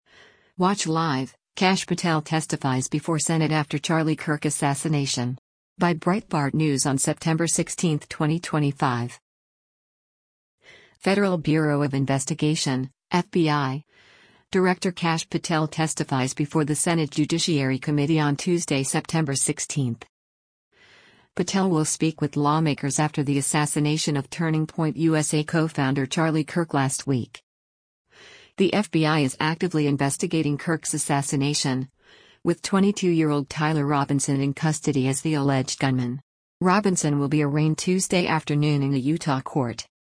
Federal Bureau of Investigation (FBI) Director Kash Patel testifies before the Senate Judiciary committee on Tuesday, September 16.